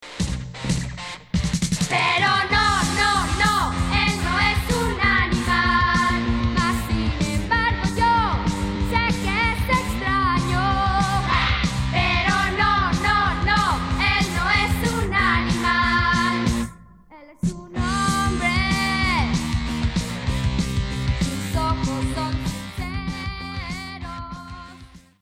rock opera
guitar
drums
bass